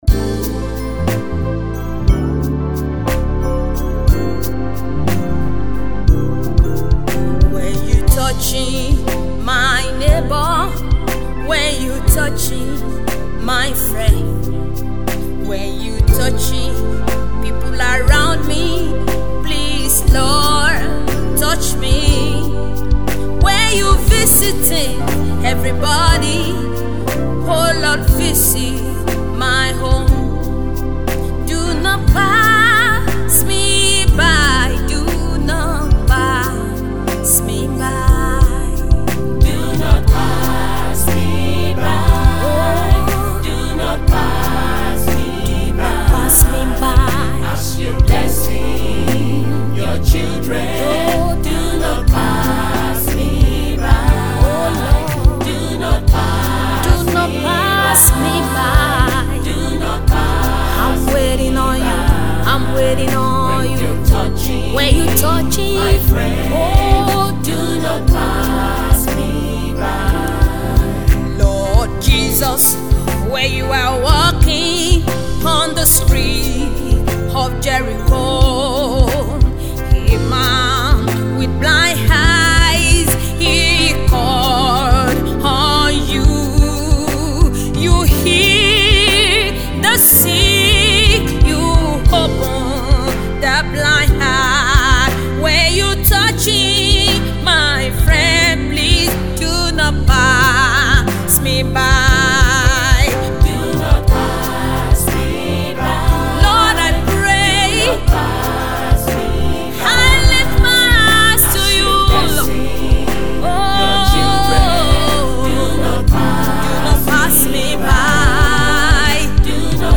Gospel
her background singers